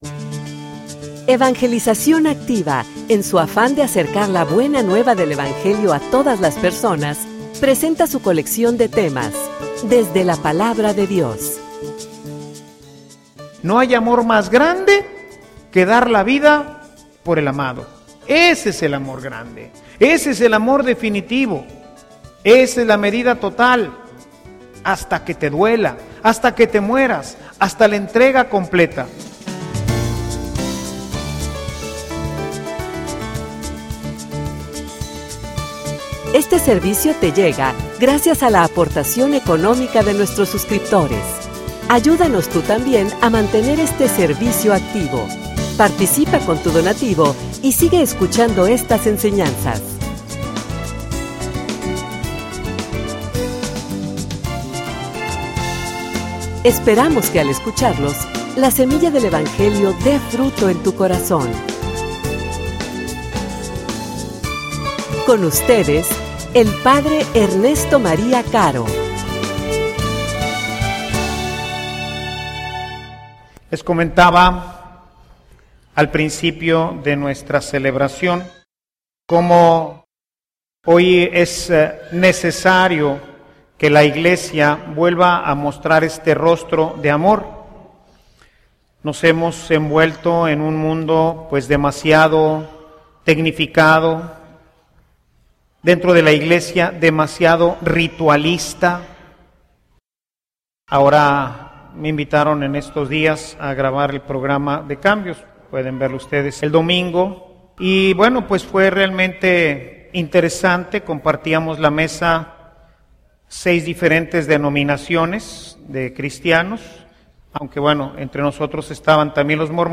homilia_Los_signos_del_amor.mp3